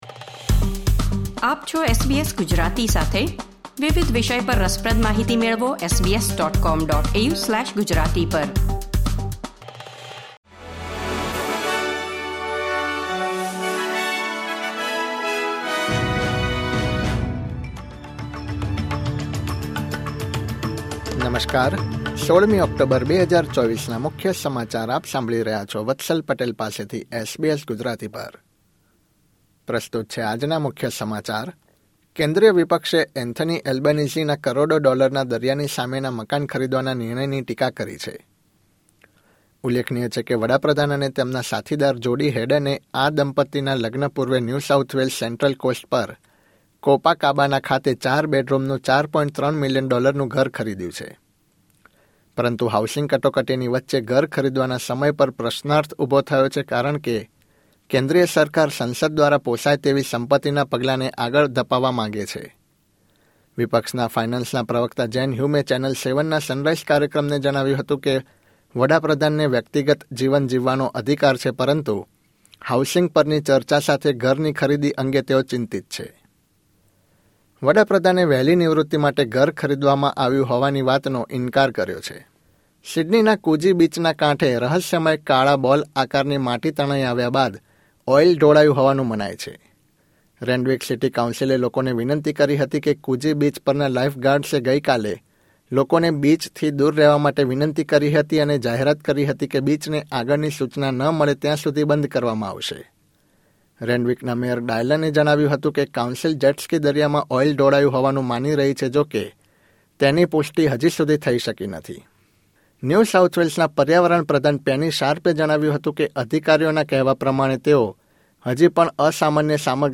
SBS Gujarati News Bulletin 16 October 2024